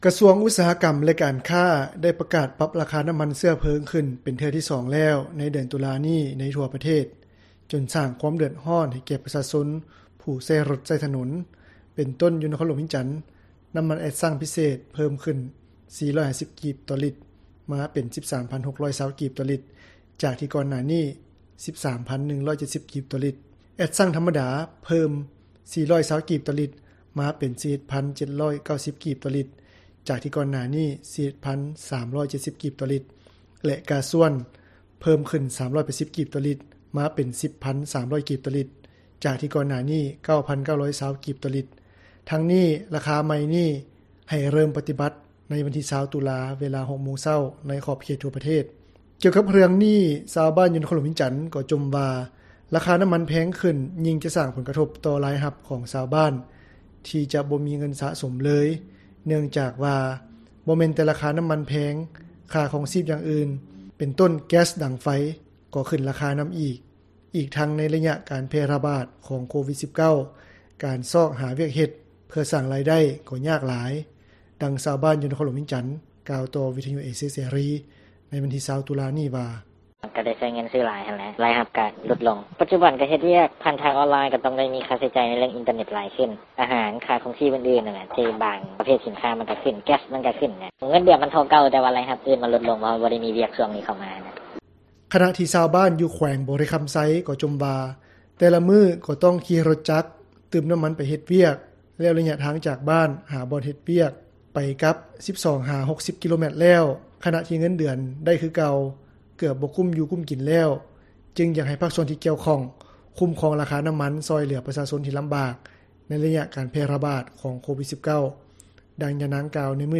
ດັ່ງຊາວບ້ານ ຢູ່ນະຄອນຫຼວງວຽງຈັນ ກ່າວຕໍ່ວິທຍຸເອເຊັຽເສຣີ ໃນວັນທີ 20 ຕຸລາ ນີ້ວ່າ: